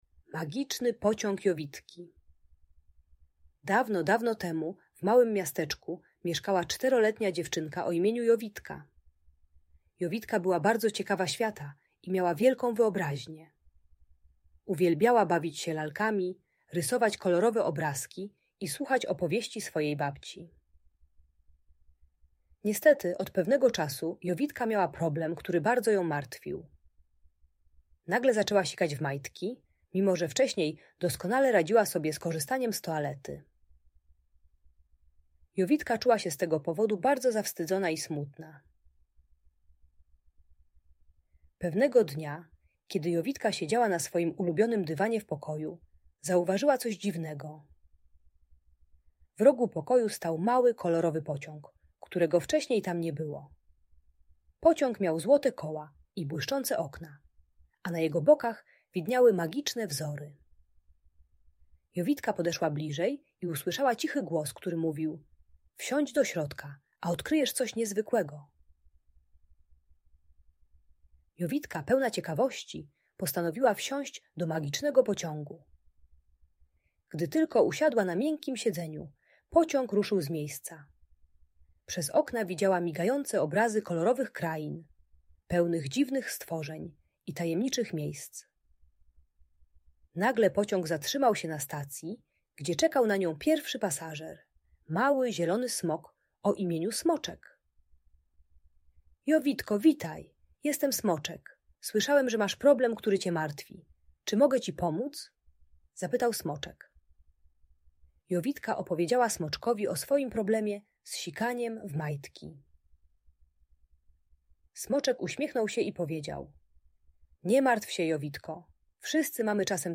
Magiczny Pociąg Jowitki - Urocza Story o Odwadze i Przyjaźni - Audiobajka dla dzieci